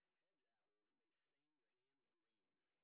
sp08_train_snr30.wav